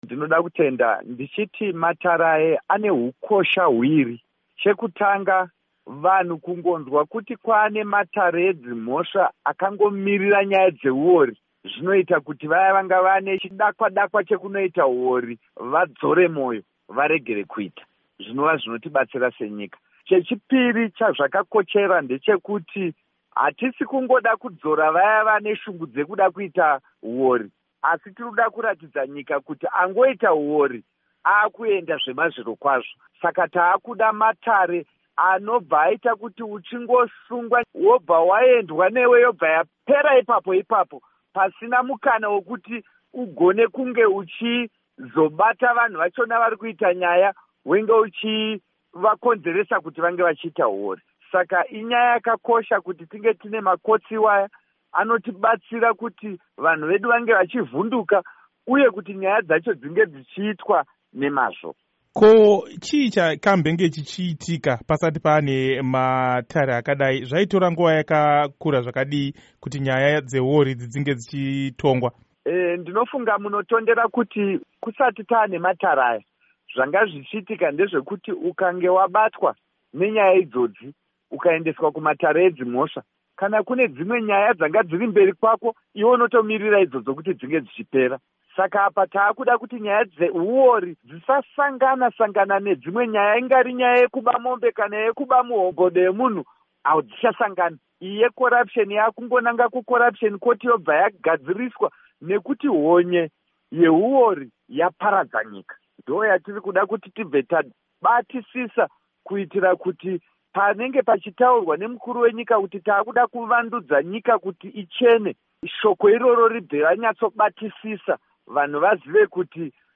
Hurukuro naVaZiyambi Ziyambi